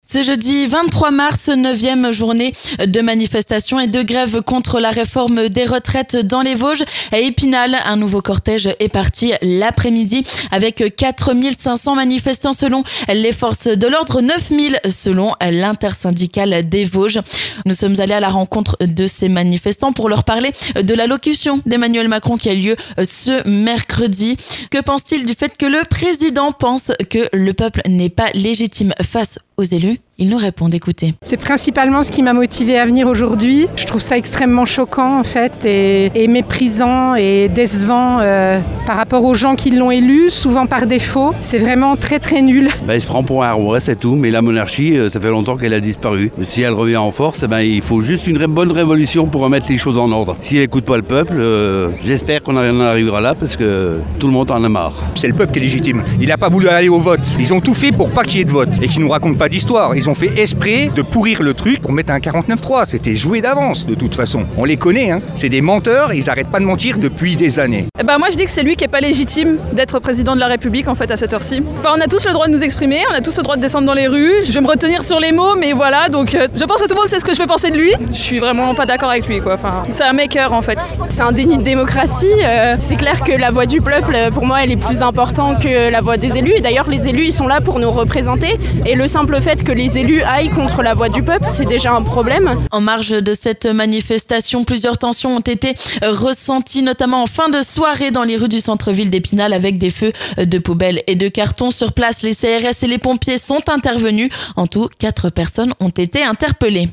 Ce jeudi après-midi, les manifestants étaient nombreux dans les rues d'Epinal pour lutter contre la réforme des retraites. 4 500 selon les forces de l'ordre, 9 000 selon les syndicats.
Nous avons slalomé entre les pancartes et les drapeaux pour demander aux manifestants ce qu'il pensait de l'allocution d'Emmanuel Macron de ce mercredi.